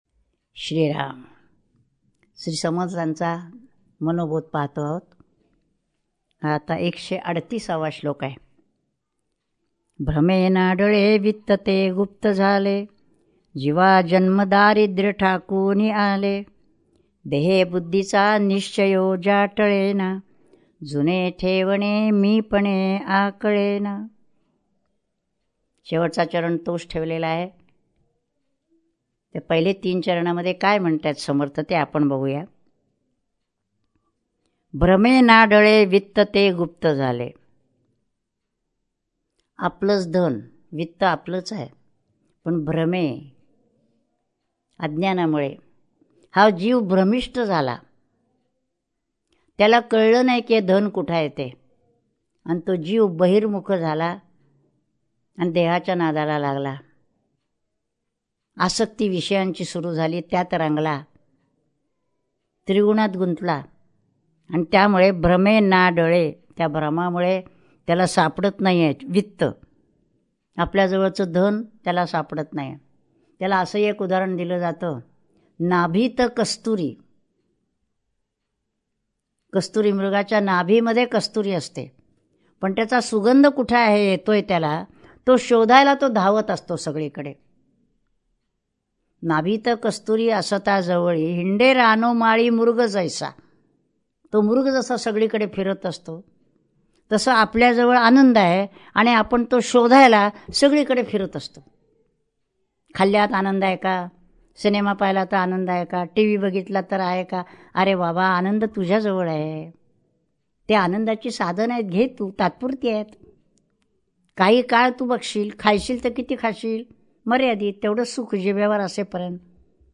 श्री मनाचे श्लोक प्रवचने श्लोक 138 # Shree Manache Shlok Pravachane Shlok 138